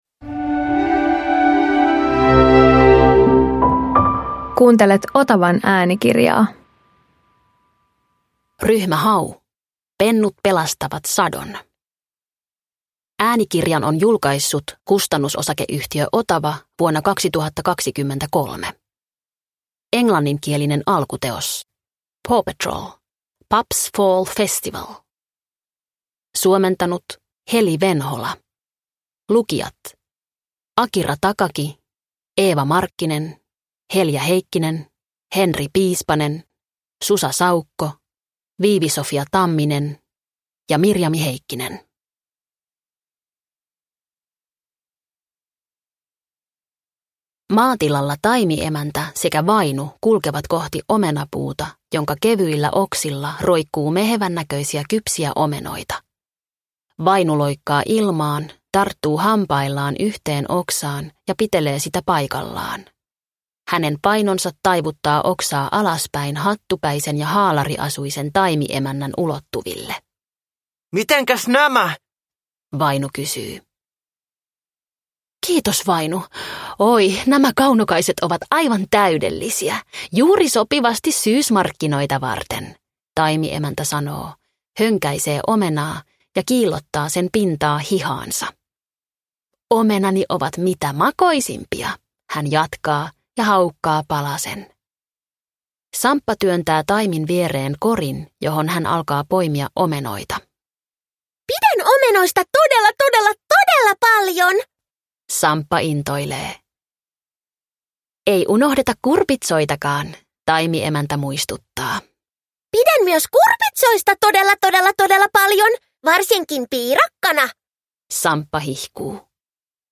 Ryhmä Hau - Pennut pelastavat sadon – Ljudbok – Laddas ner